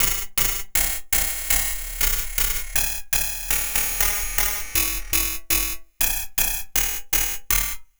Ring Mod Beat.wav